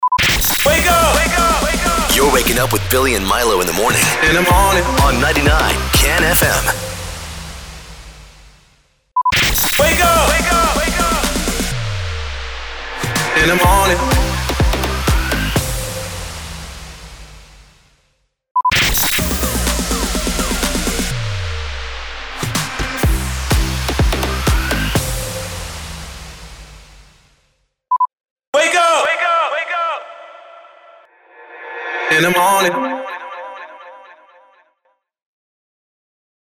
273 – SWEEPER – MORNING SHOW
273-SWEEPER-MORNING-SHOW.mp3